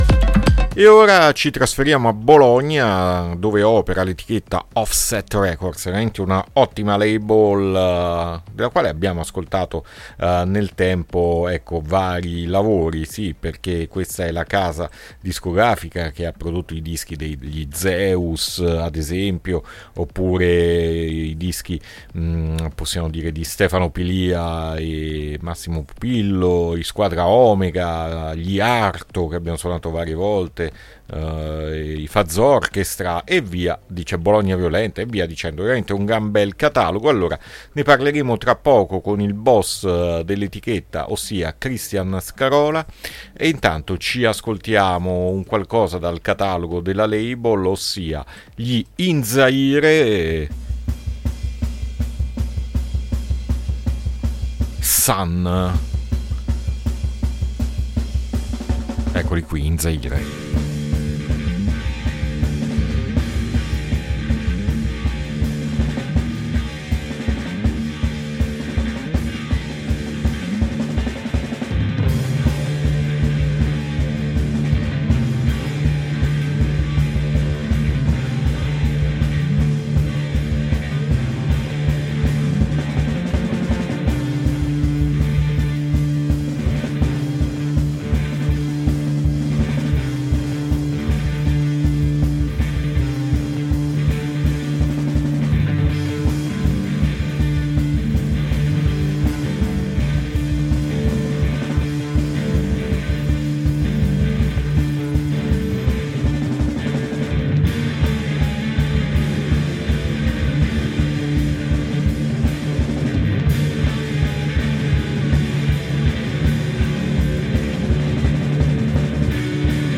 INTERVISTA OFFSET RECORDS AD ALTERNITALIA 4-8-2023